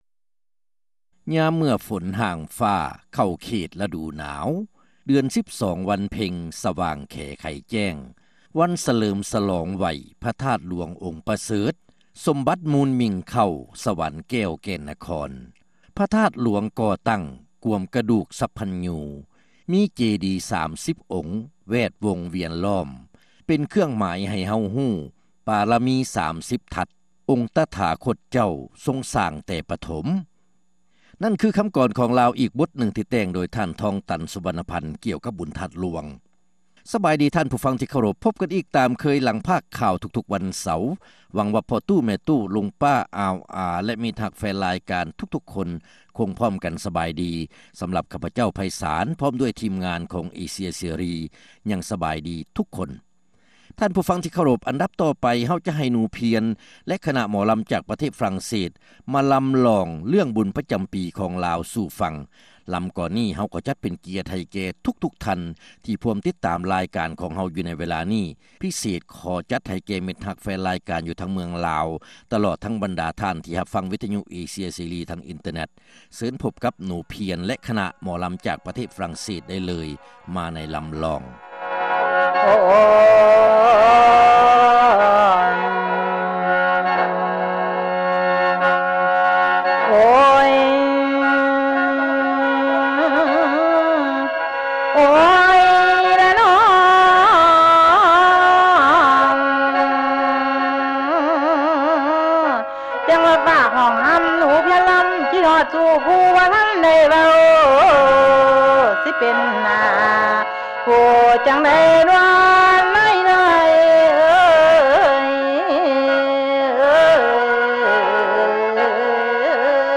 ຣາຍການໜໍລຳ ປະຈຳສັປະດາ ວັນທີ 3 ເດືອນ ພຶສະຈິກາ ປີ 2006